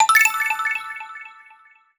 treasure.wav